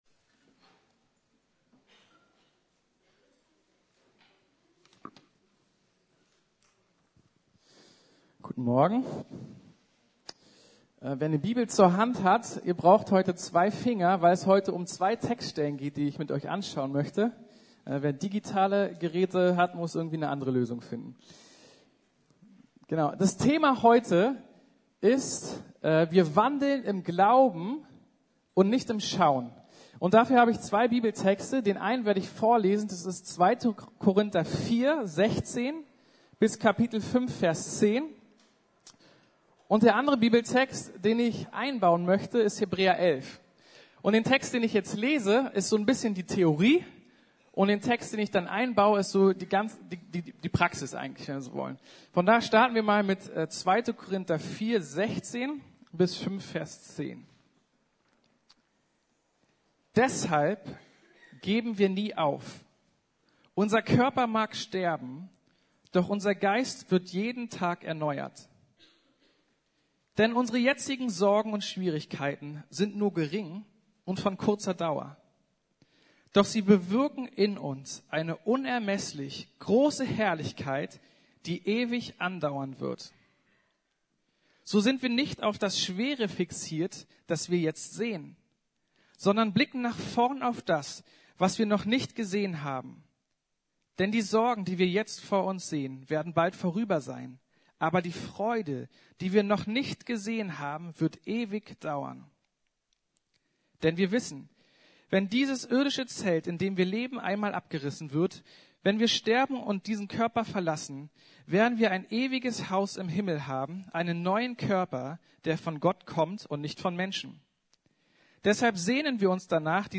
Glaubenshelden ~ Predigten der LUKAS GEMEINDE Podcast